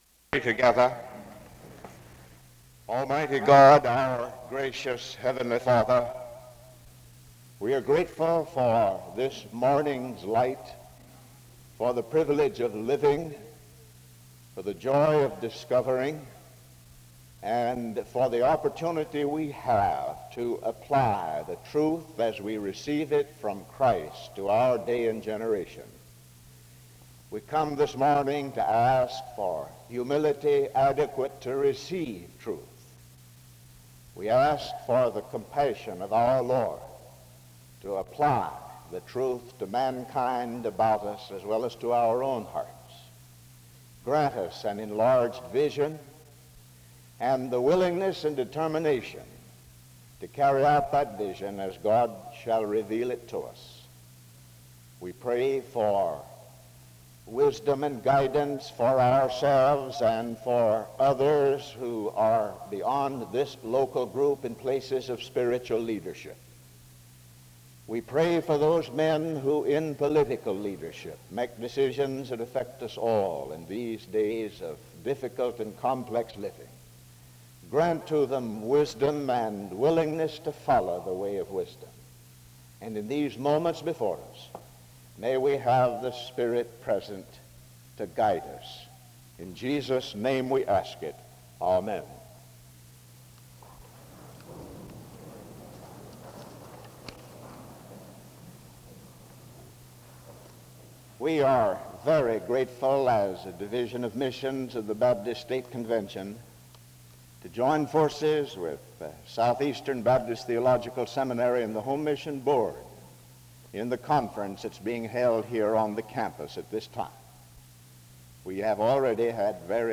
The service begins with an opening word of prayer from 0:00-1:31.
Announcements and an introduction to the speaker are given from 1:41-3:16.
He preaches on Southern agriculture and farming. A closing prayer is offered from 39:39-39:59.